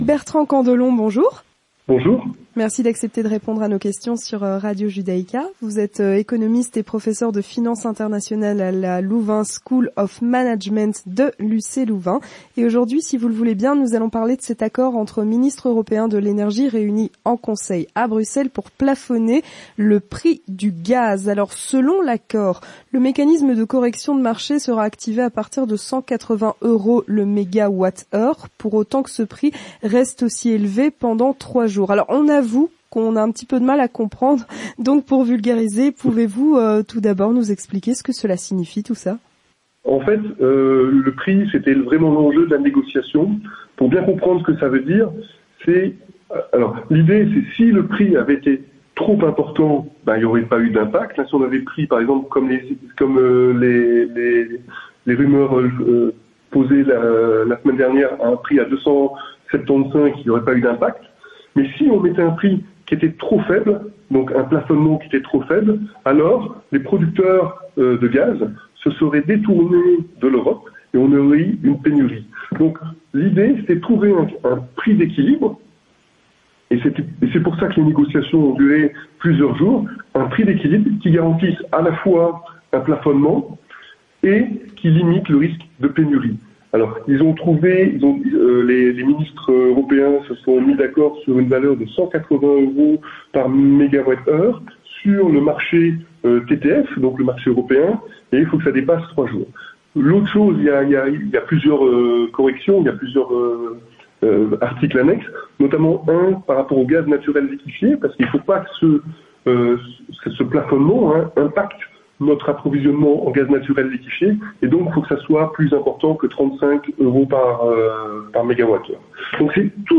L'Entretien du Grand Journal